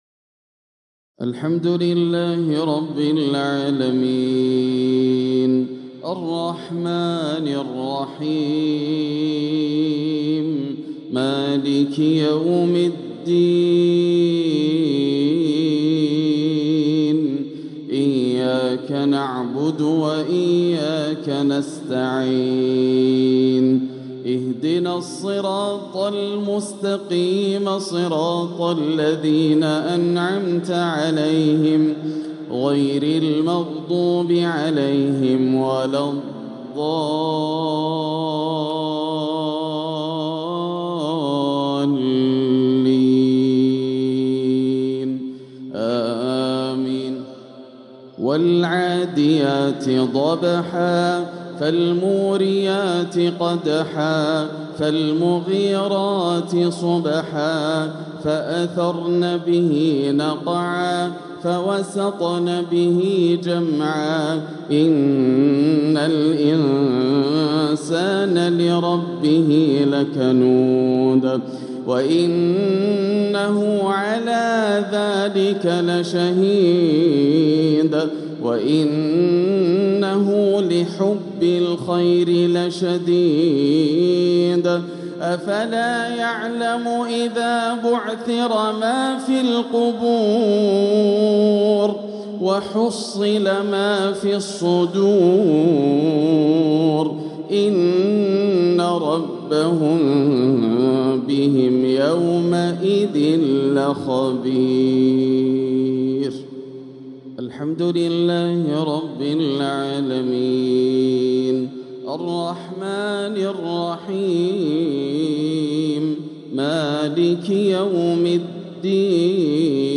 العقد الآسر لتلاوات الشيخ ياسر الدوسري تلاوات شهر جمادى الآخرة عام ١٤٤٦ هـ من الحرم المكي > سلسلة العقد الآسر من تلاوات الشيخ ياسر > الإصدارات الشهرية لتلاوات الحرم المكي 🕋 ( مميز ) > المزيد - تلاوات الحرمين